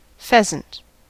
Ääntäminen
Ääntäminen : IPA : /ˈfɛz.ənt/ US : IPA : [ˈfɛz.ənt] Haettu sana löytyi näillä lähdekielillä: englanti Käännös Substantiivit 1.